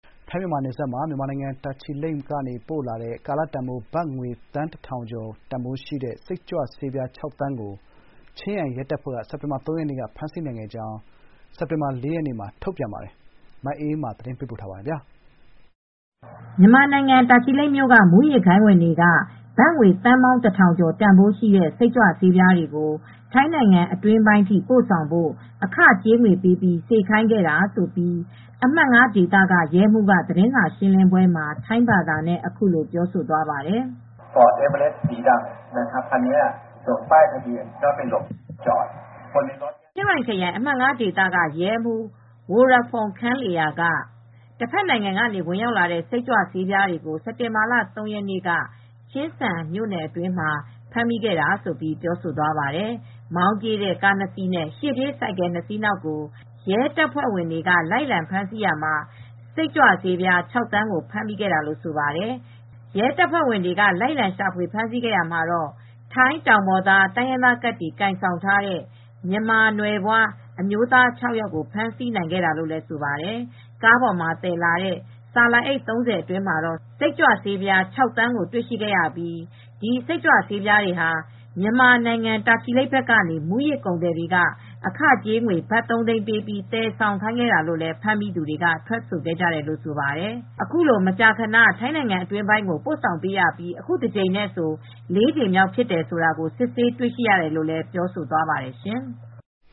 မြန်မာနိုင်ငံ တာချီလိတ်ဘက်ကနေ ထိုင်းနိုင်ငံအတွင်းပိုင်းကို ပို့နေတဲ့ စိတ်ကြွဆေးပြား ၆ သန်း ဖမ်းမိခဲ့ကြောင်း ထိုင်းရဲတပ်ဖွဲ့ရဲ့ သတင်းစာရှင်းလင်းပွဲ။ (စက်တင်ဘာ ၄၊ ၂၀၂၄)
မြန်မာနိုင်ငံ တာချီလိတ်မြို့က မူးယစ်ဂိုဏ်းဝင်တွေက ဘတ်ငွေသန်း ၁,၀၀၀ ကျော် တန်ဘိုးရှိတဲ့ စိတ်ကြွဆေးပြားတွေကို ထိုင်းနိုင်ငံအတွင်းပိုင်းအထိပို့ဆောင်ဖို့ အခကြေးငွေပေးပြီးစေခိုင်းခဲ့တာြဖစ်ကြောင်း ချင်းရိုင်ခရိုင် အမှတ် (၅) ဒေသ ရဲမှူး ဝိုရဖုန်း ခန်လေရာက Pol. Maj. Gen. Worapong Khamluea က သတင်းစာရှင်းလင်းပွဲမှာ ထိုင်းဘာသာနဲ့ အခုလိုပြောသွားပါတယ်။